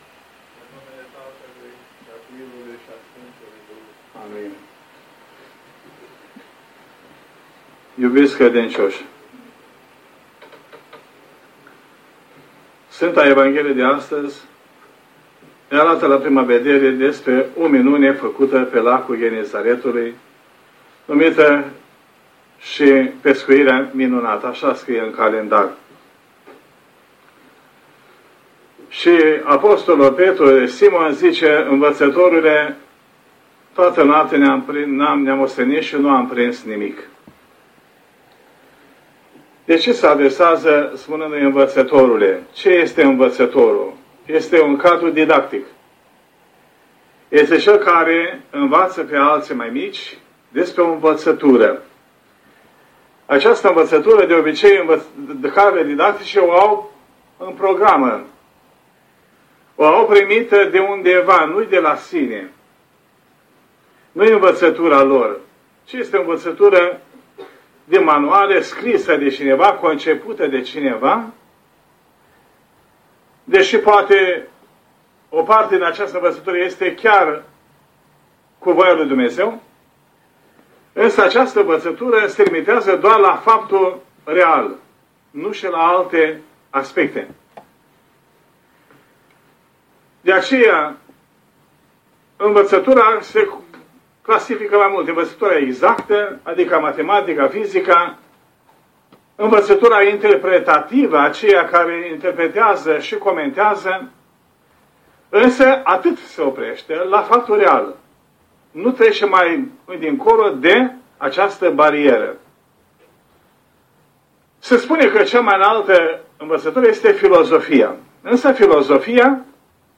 predică